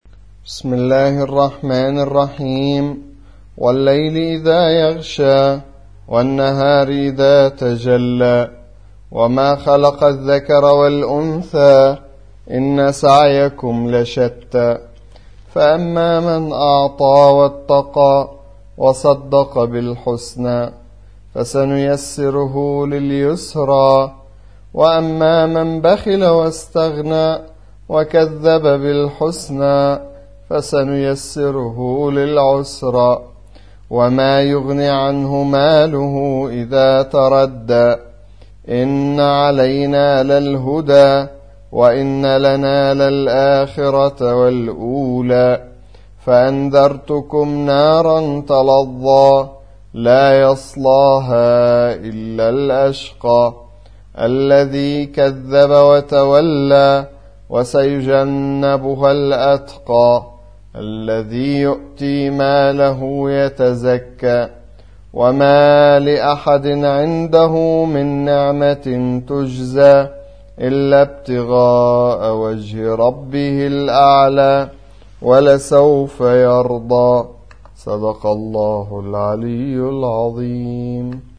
موقع يا حسين : القرآن الكريم 92. سورة الليل - سورة مكية ، عدد آياتها : 21 لحفظ الملف في مجلد خاص اضغط بالزر الأيمن هنا ثم اختر (حفظ الهدف باسم - Save Target As) واختر المكان المناسب